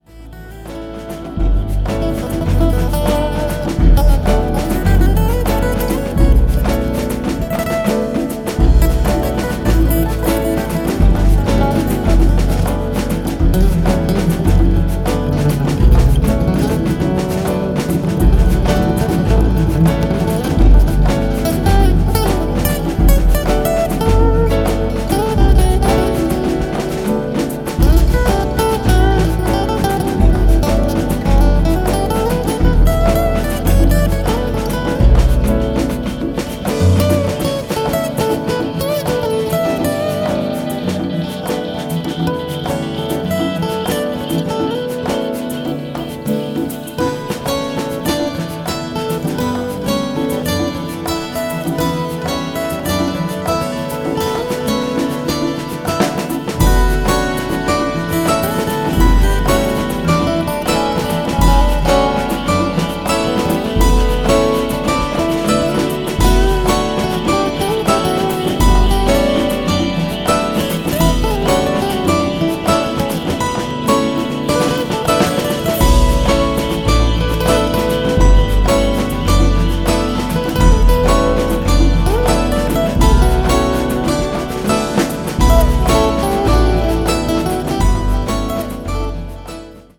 Electronix House